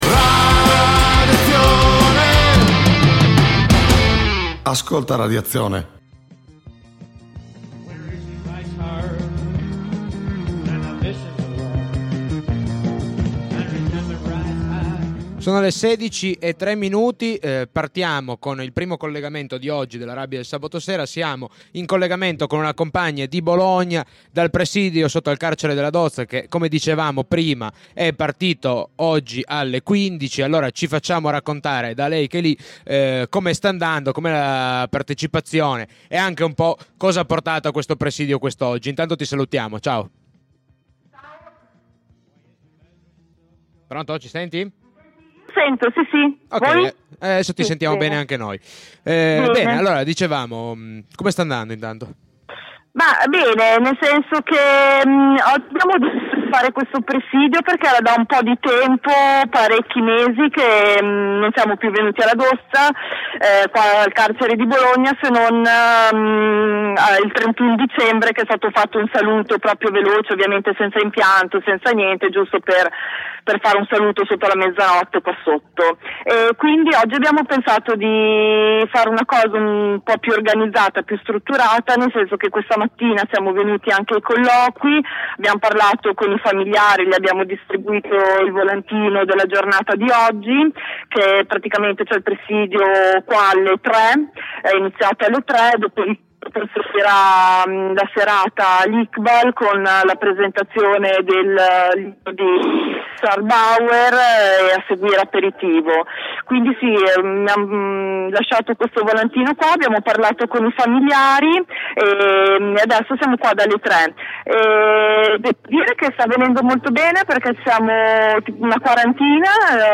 Ci colleghiamo con il presidio davanti al carcere della Dozza (BO). Molti compagni da varie cittá si sono riuniti di fronte alla prigione per far sentire la propria vicinanza alle persone ivi recluse, con la volontá di rompere il muro d’isolamento che lo Stato continua ad innalzare tra chi sta dentro e chi fuori.
Di seguito l’intervista